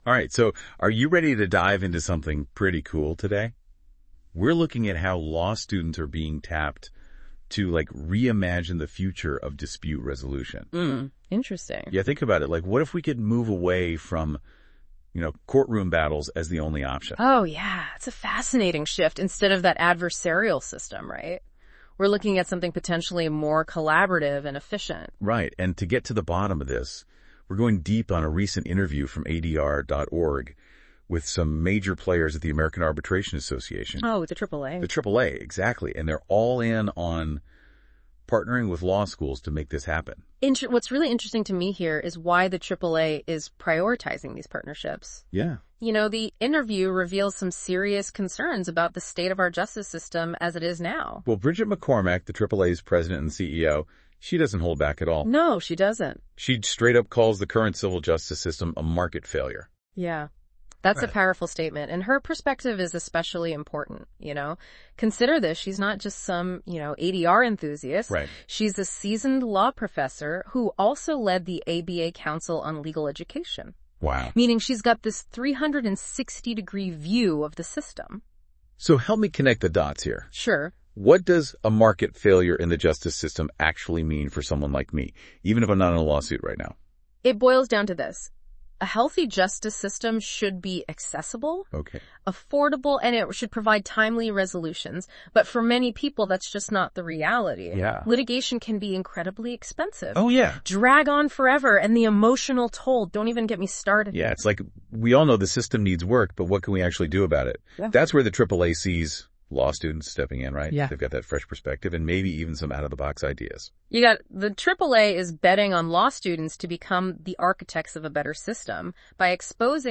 This interview is part of our ongoing series that highlights the contributions of AAA employees in advancing our mission and initiatives.